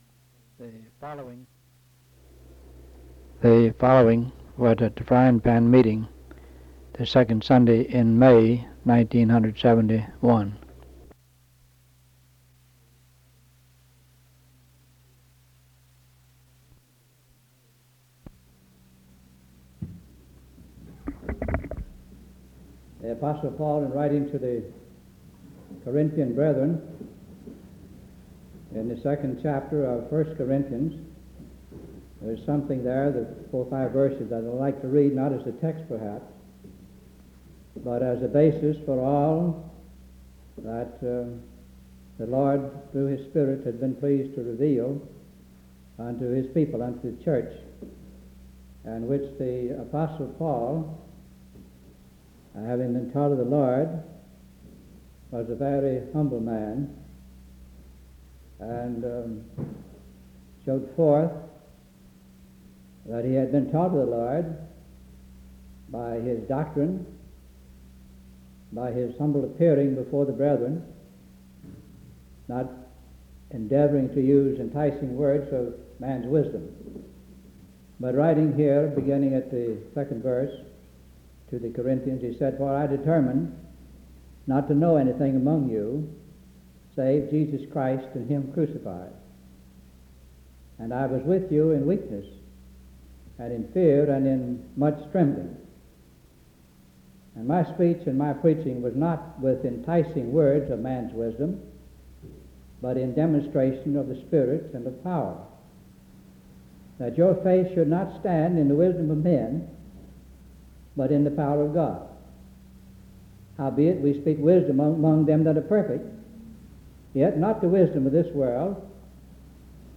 Herndon (Va.)